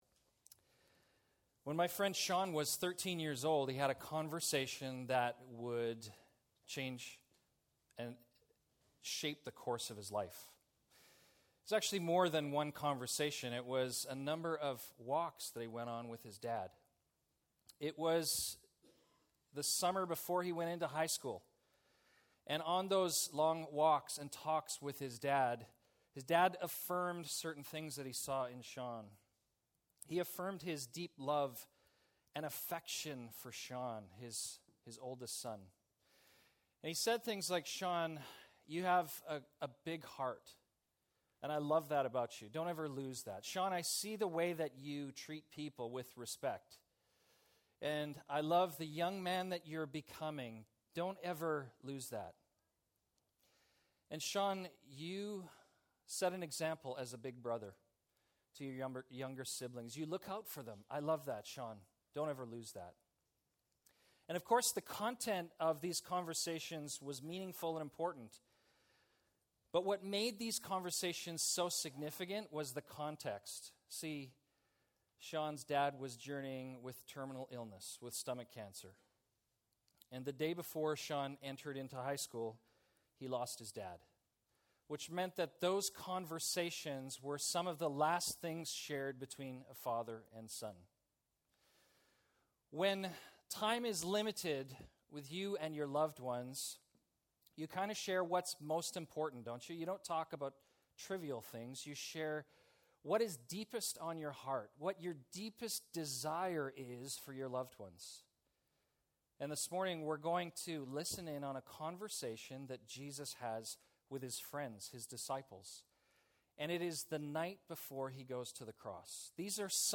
Episode from Tenth Church Sermons